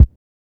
C (KICK) Dro Kick.wav